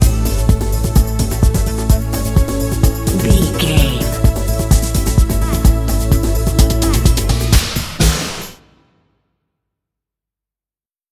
Epic / Action
Aeolian/Minor
Fast
drum machine
synthesiser
strings